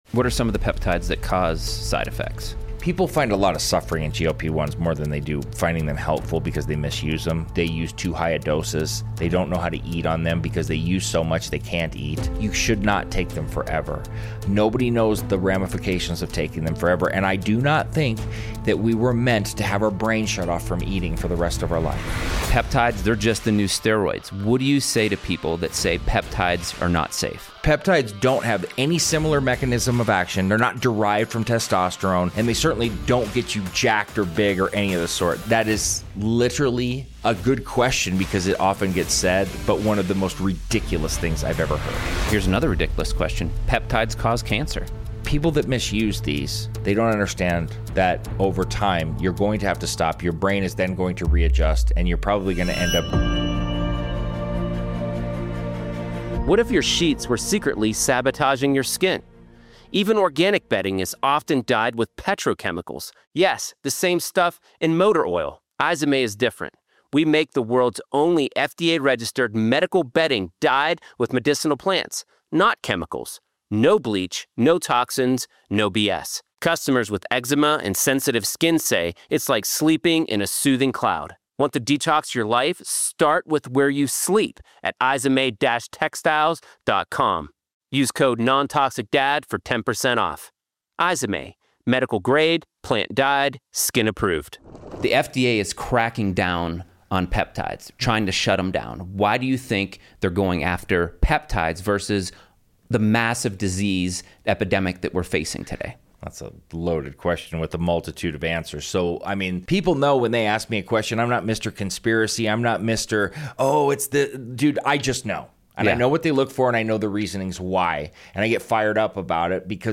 If you find the conversation helpful, share it with someone who’s ready to take charge of their health and live in harmony with nature.